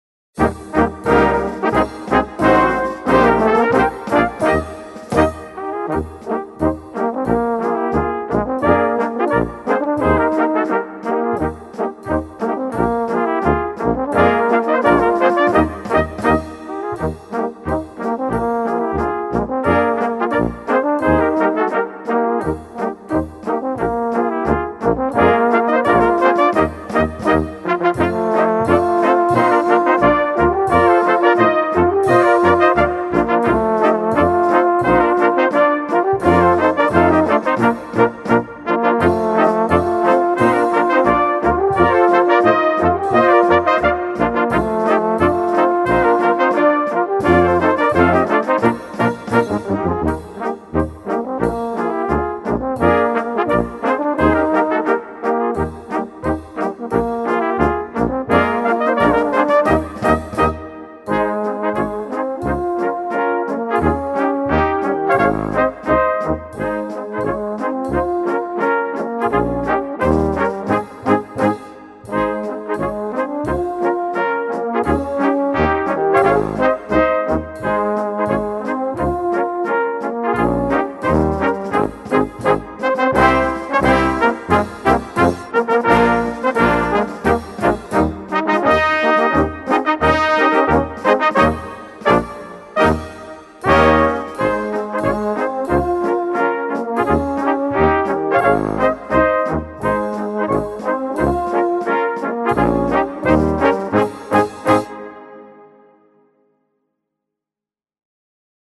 Catégorie Harmonie/Fanfare/Brass-band
Sous-catégorie Polka
Instrumentation Ha (orchestre d'harmonie)